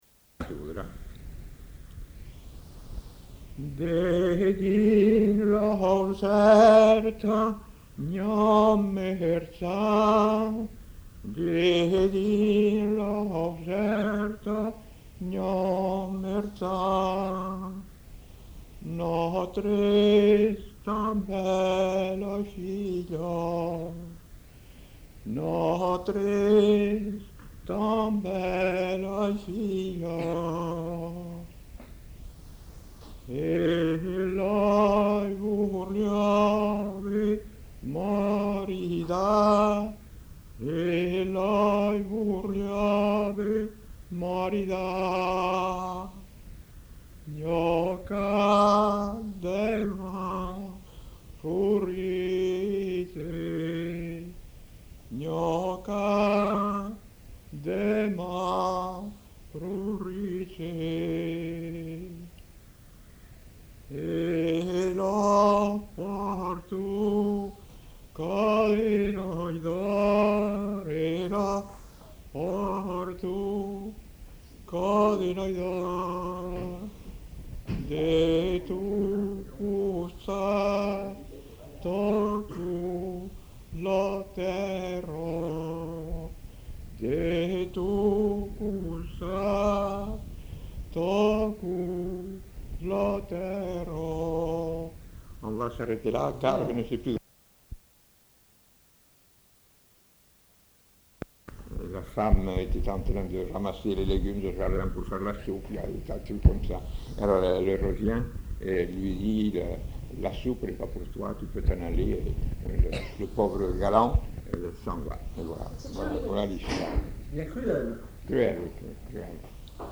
Aire culturelle : Quercy
Lieu : Marminiac
Genre : chant
Effectif : 1
Type de voix : voix d'homme
Production du son : chanté ; parlé
Notes consultables : Après l'interprétation du chant, résume la fin du chant.